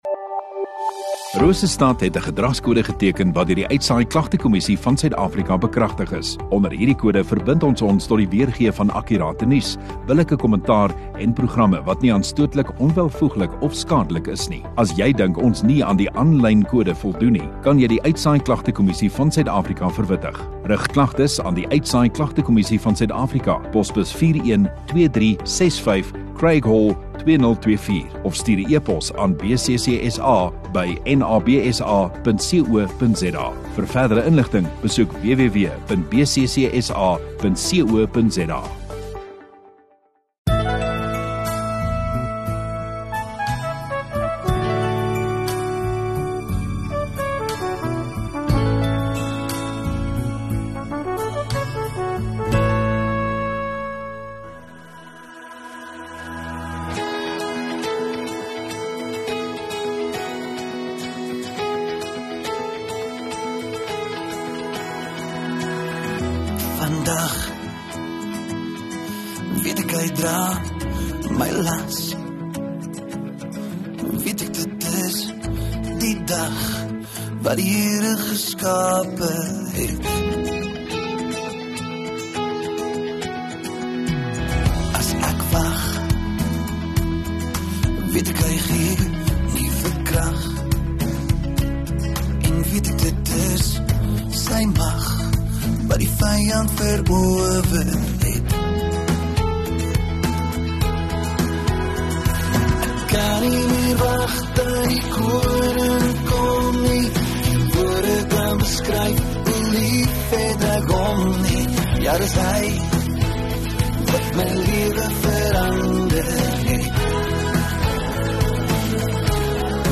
8 Jun Saterdag Oggenddiens